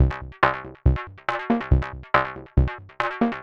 tx_synth_140_multicomb_C2.wav